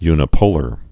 (ynĭ-pōlər)